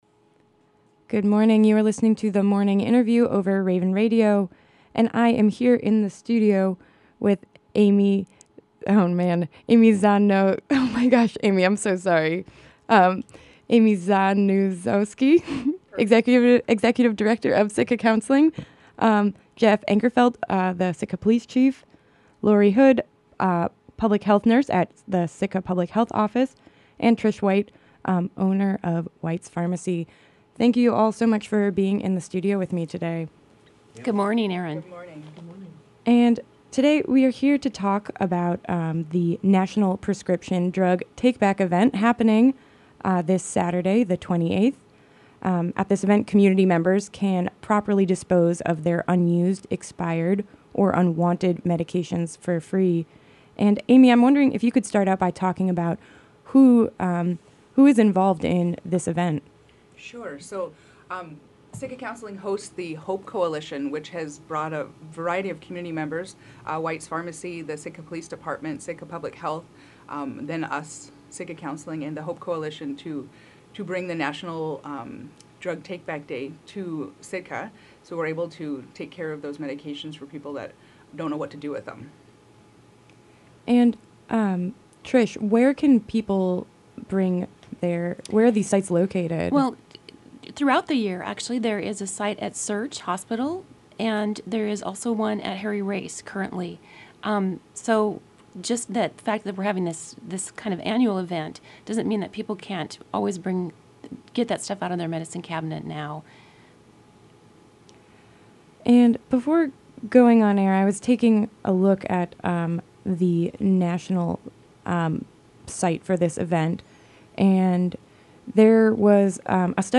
joined us in the studio